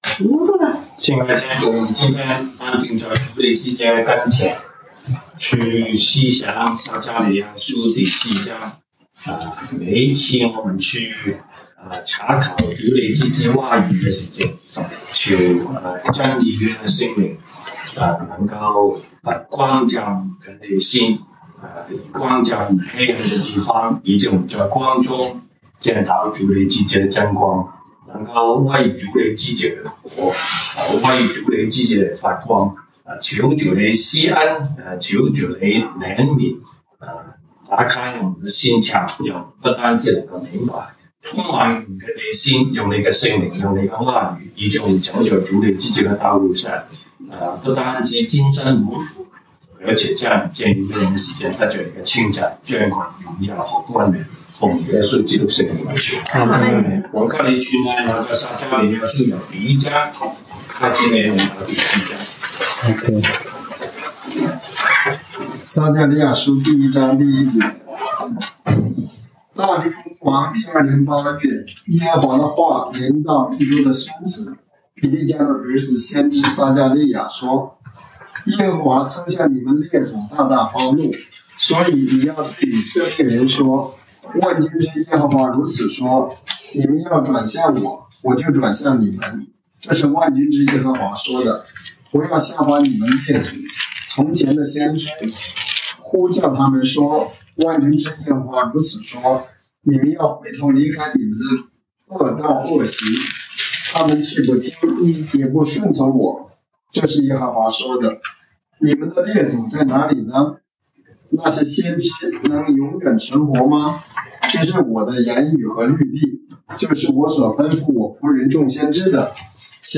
Monday Bible Study